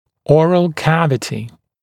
[‘ɔːrəl ‘kævətɪ][‘о:рэл ‘кэвэти]полость рта